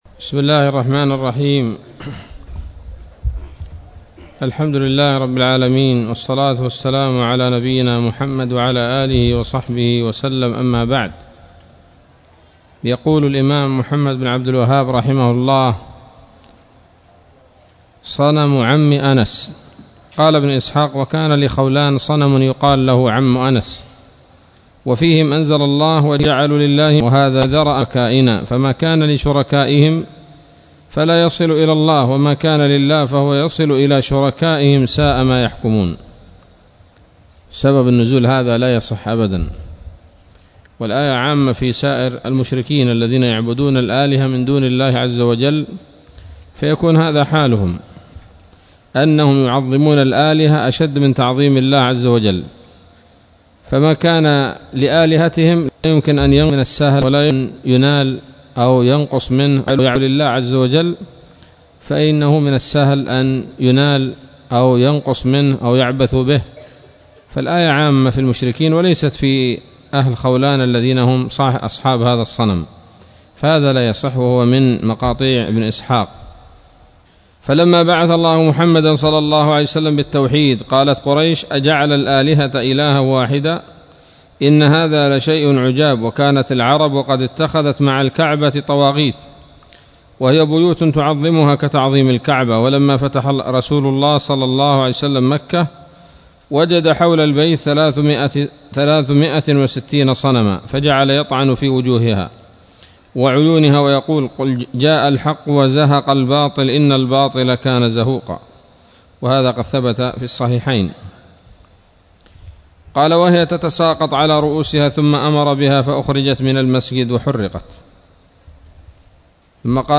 الدرس السابع عشر من مختصر سيرة الرسول ﷺ